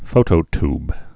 (fōtō-tb, -tyb)